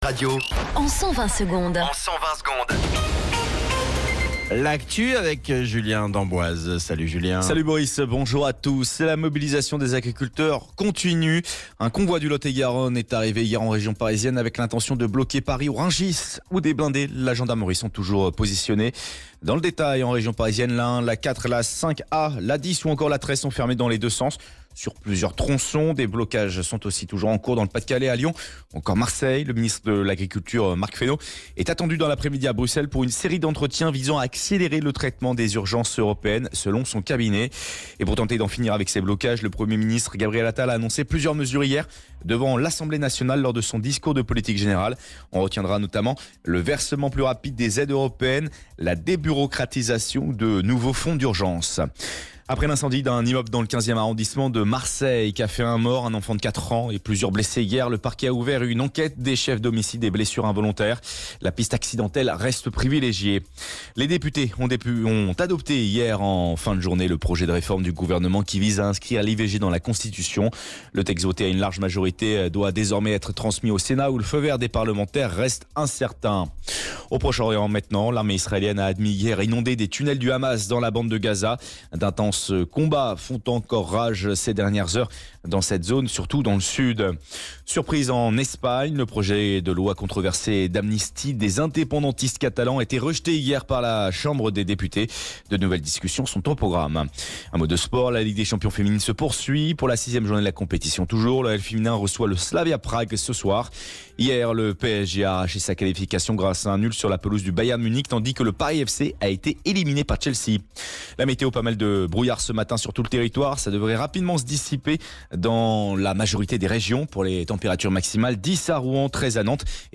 Flash Info National 31 Janvier 2024 Du 31/01/2024 à 07h10 Flash Info Télécharger le podcast Partager : À découvrir Alerte Canicule : Le Eddie’s Dive Bar d’Iron Maiden débarque en France !